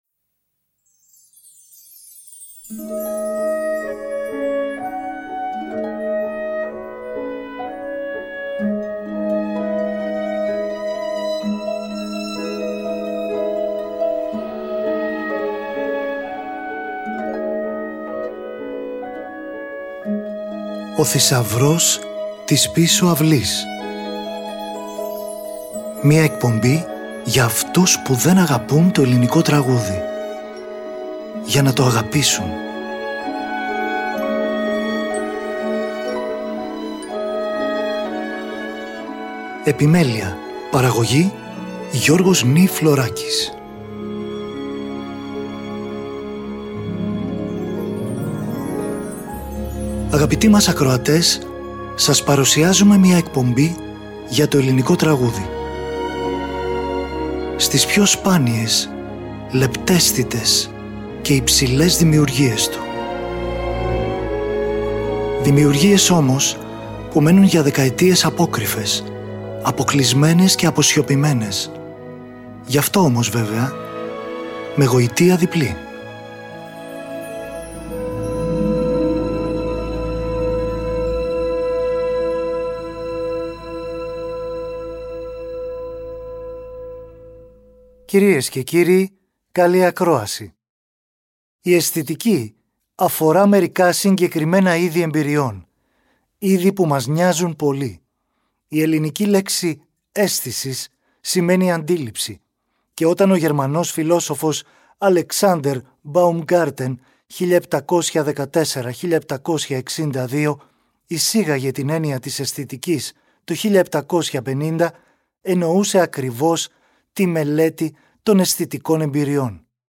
Τρίτο Πρόγραμμα 90,9 & 95,6